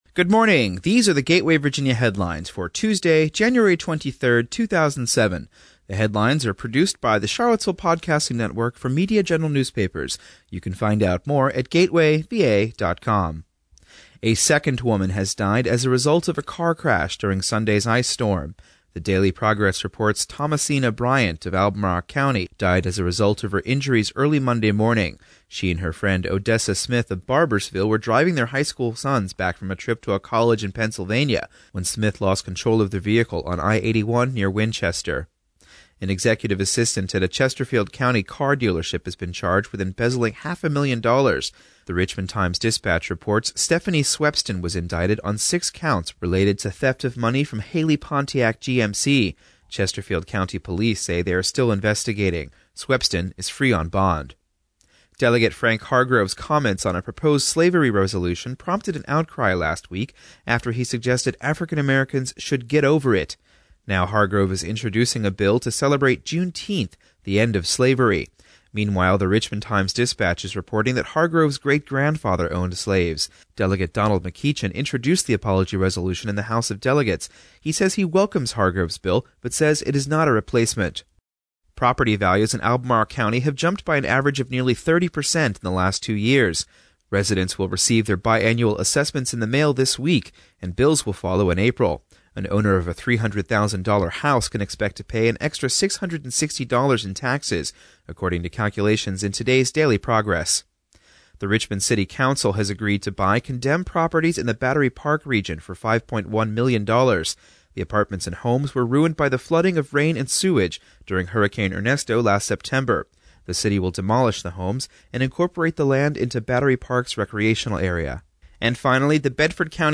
The Gateway Virginia Headlines offer a brief audio summary of the latest news in Central and Western Virginia.